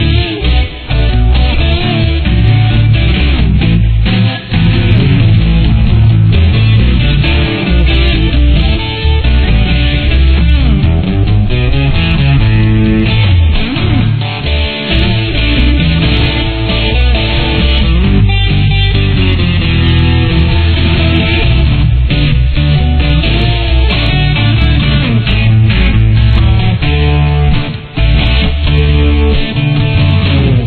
Intro Solo